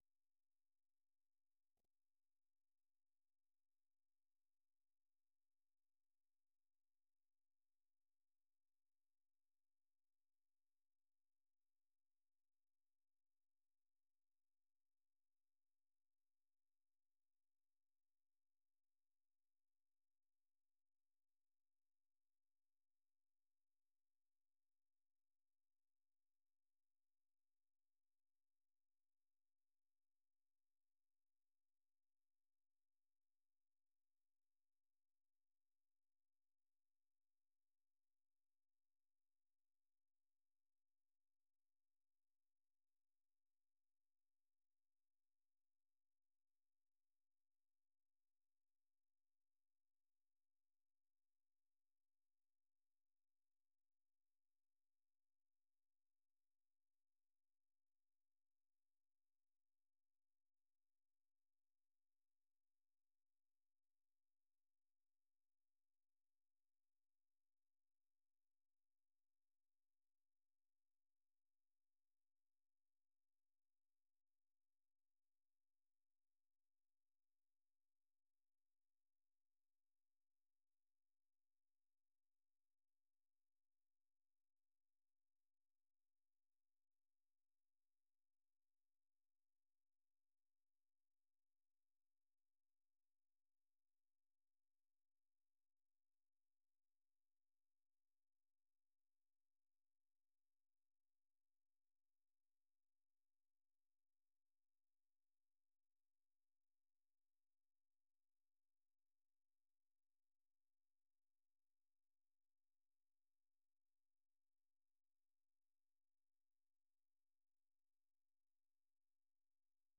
لومړنۍ سهارنۍ خبري خپرونه
په دې نیم ساعته خپرونه کې د افغانستان او نړۍ تازه خبرونه، مهم رپوټونه، مطبوعاتو ته کتنه او مرکې شاملې دي.